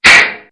metal Impact Bullet Sound.wav